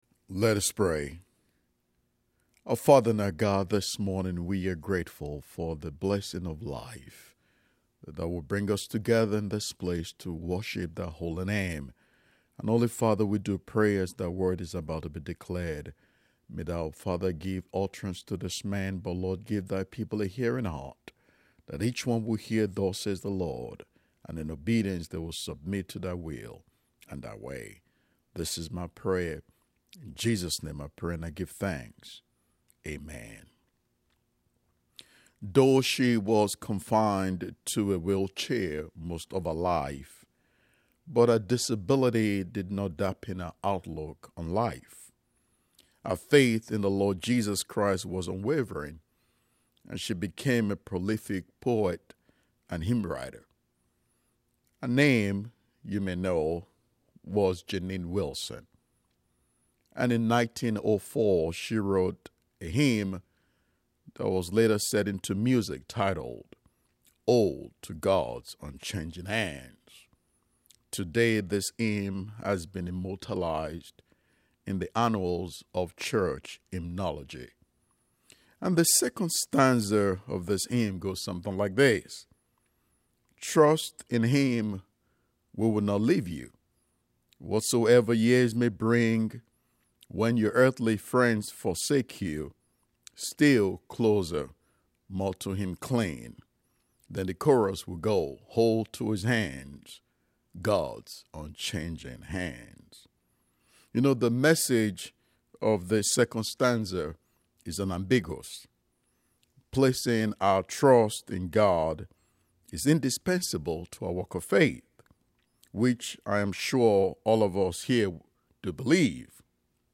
10:30 AM Service Don’t Miss Your Blessing Click to listen to the sermon. https